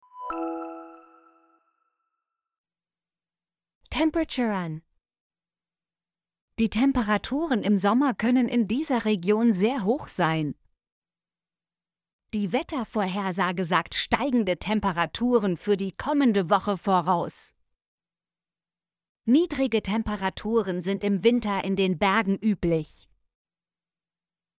temperaturen - Example Sentences & Pronunciation, German Frequency List